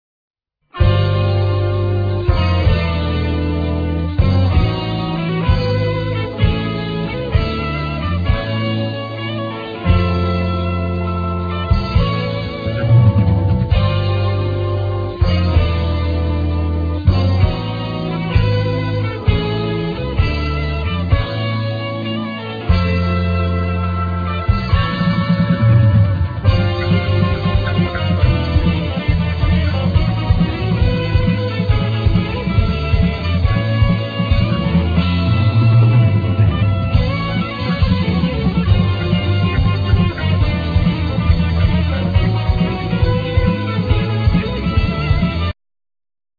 Keyboards
Synthesizers
Flute
Guiatr
Bass
Drums